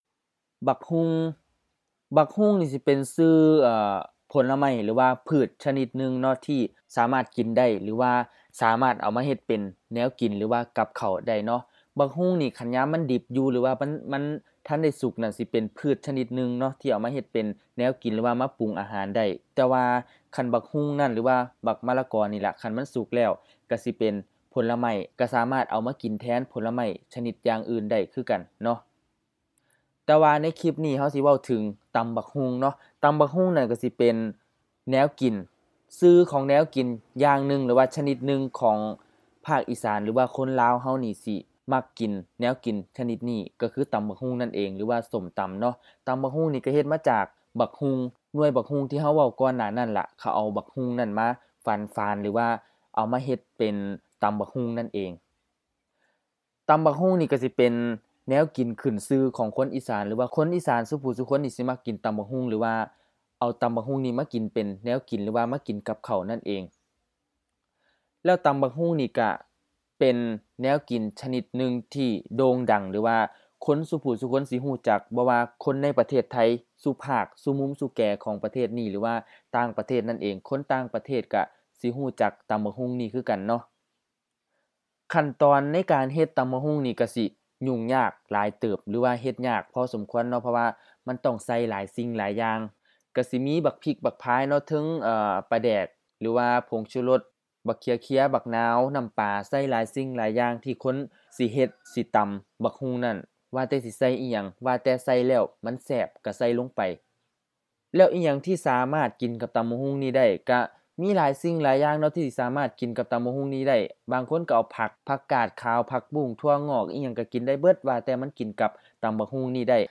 บักหุ่ง bak-huŋ M-H มะละกอ papaya